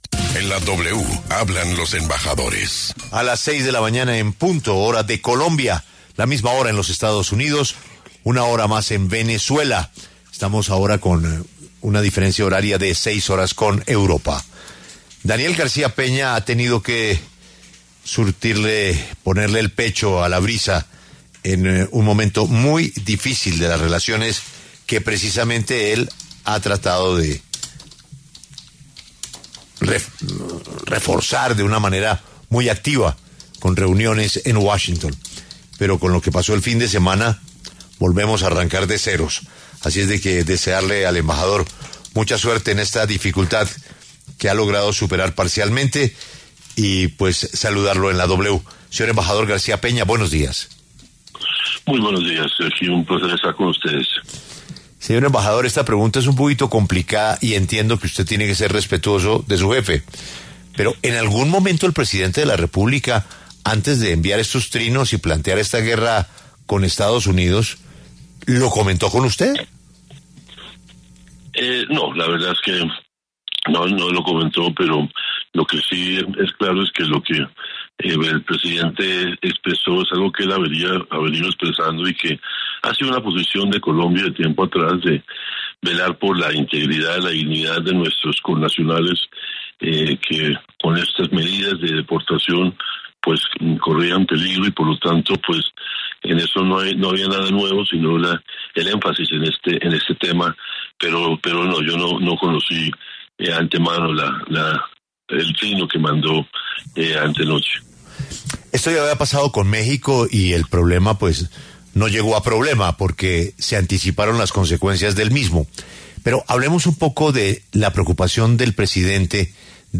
El embajador de Colombia en Estados Unidos aseguró en La W que los dos países lograron un acuerdo que beneficiará a la relación binacional.